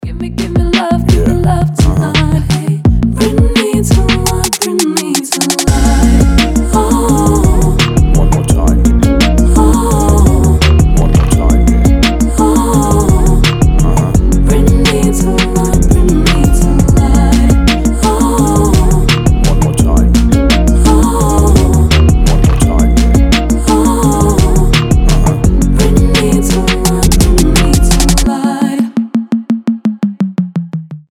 поп
Electronic
чувственные
RnB